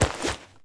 foley_combat_fight_grab_throw_01.wav